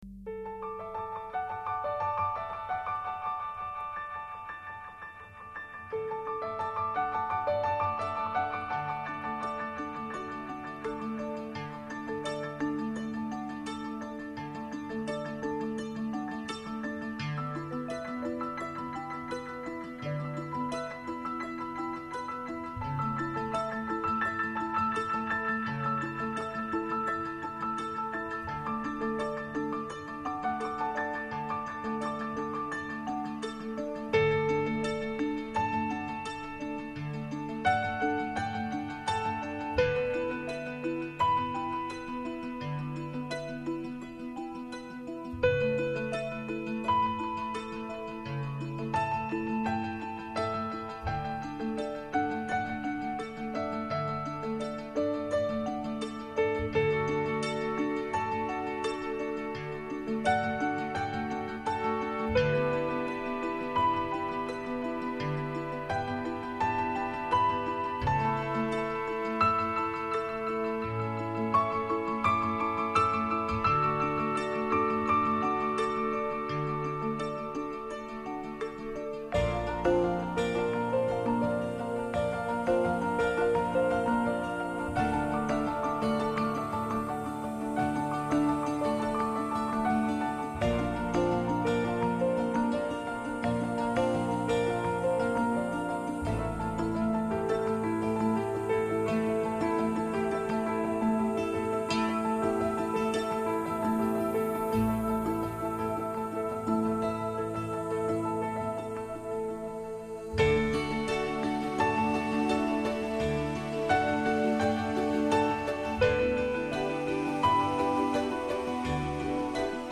DISC 2：ACOUSTIC 原音音樂